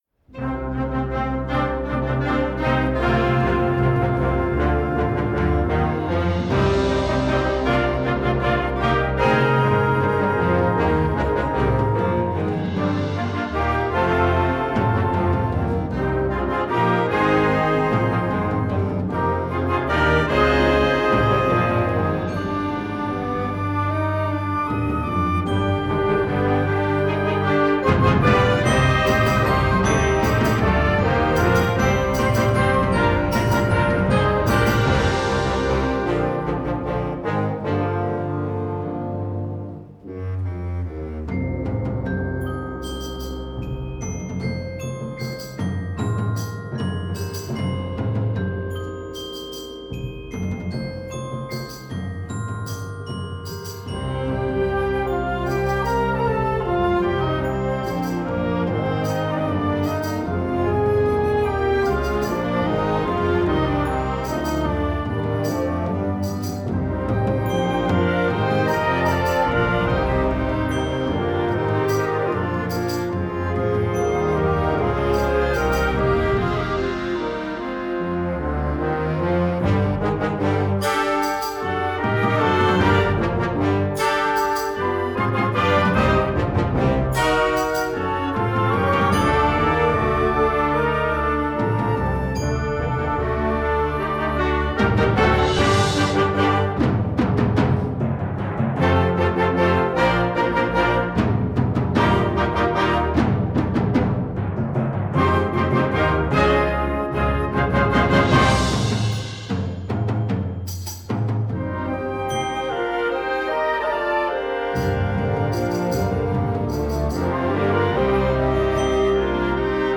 Gattung: Konzertwerk für Jugendblasorchester
Besetzung: Blasorchester
Dramatik und Spannung